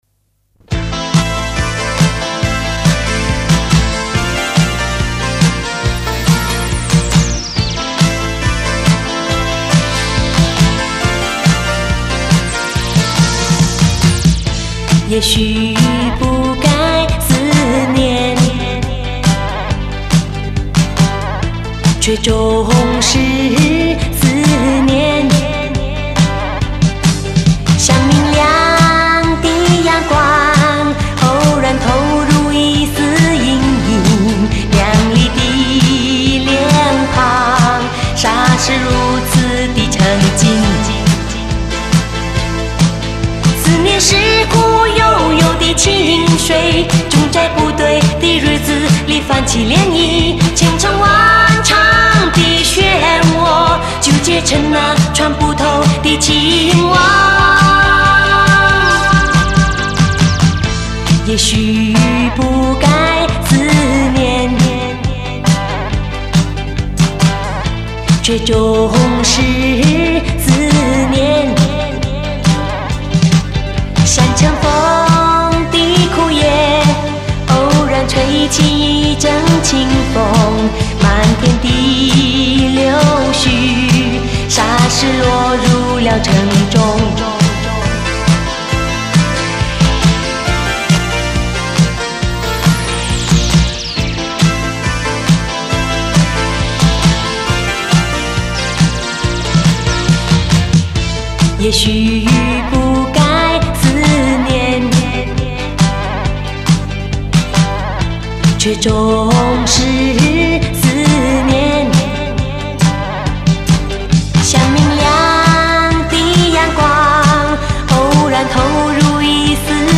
总觉得她灌录第九张专辑时患了严重的感冒， 鼻音浓到不行。。